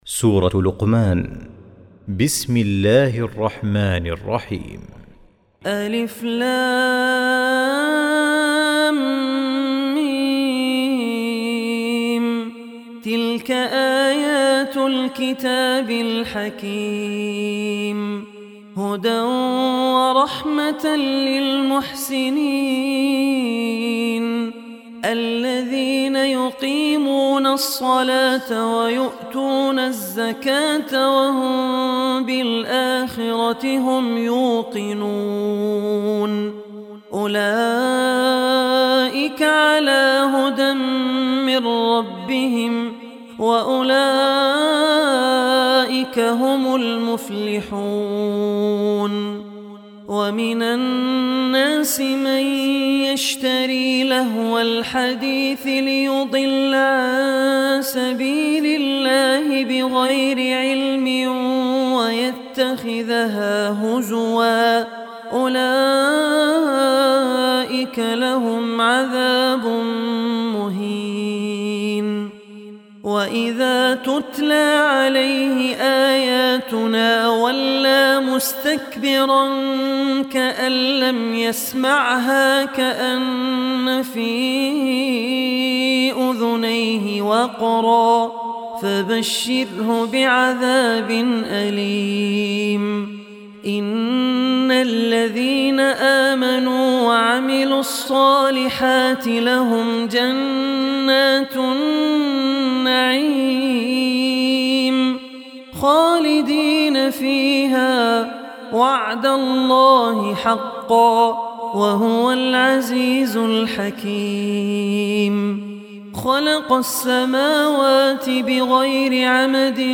Surah Luqman Recitation